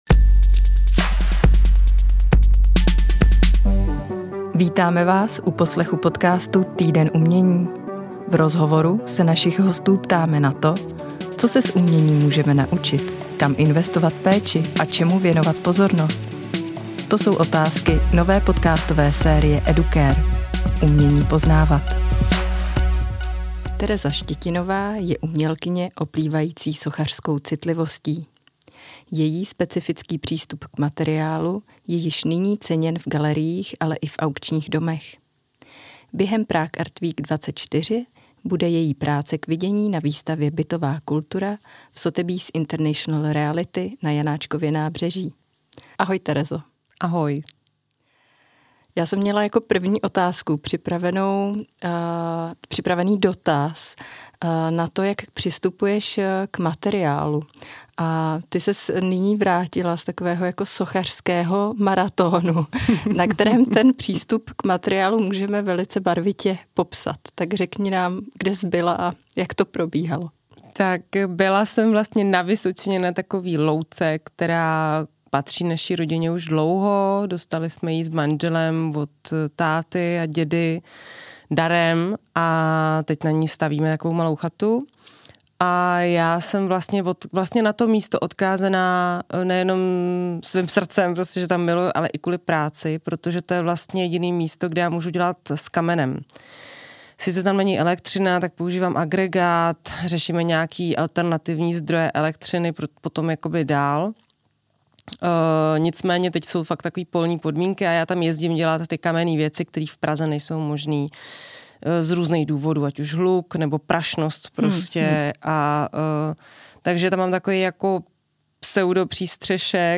Poslechněte si rozhovor o její tvorbě, uměleckých přístupech i práci pedagožky na Základní umělecké škole na Proseku.